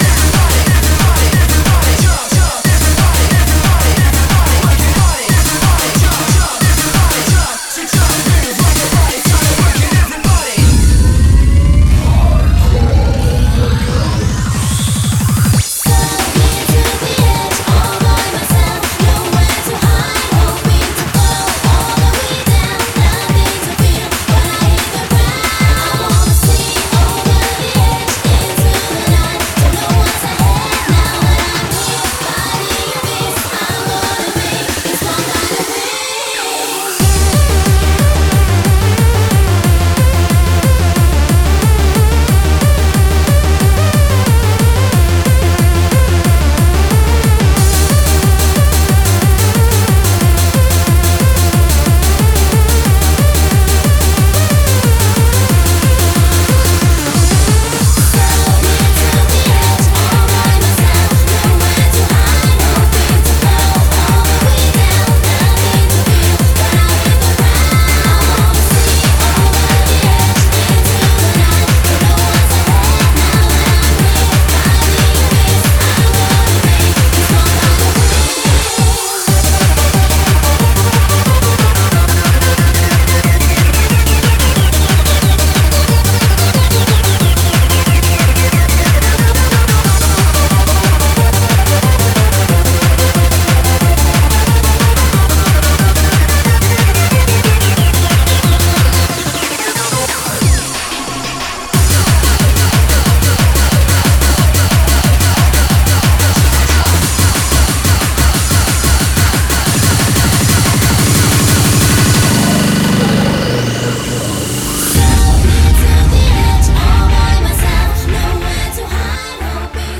BPM91-182
Audio QualityPerfect (High Quality)
I don't JUST step speedy hardcore, I swear...
Beware of speedups near the beginning and end.